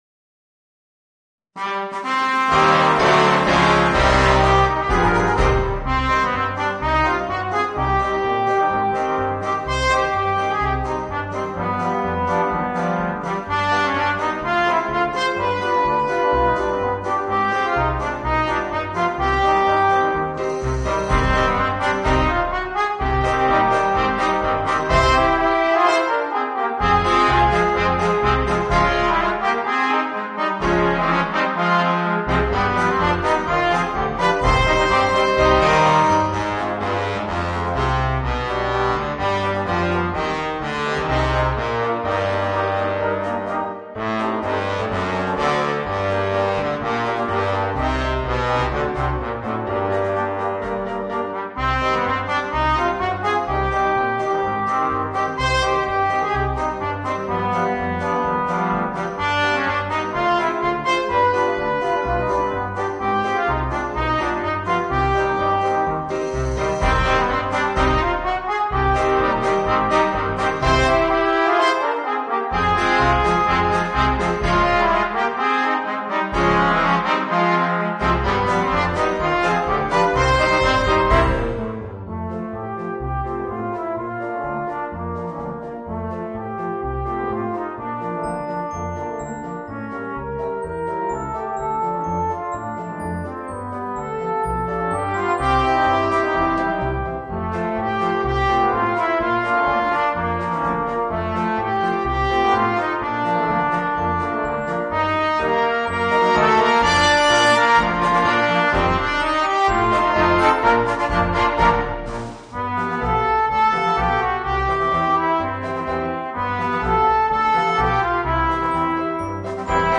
Voicing: 4 Trombones and Piano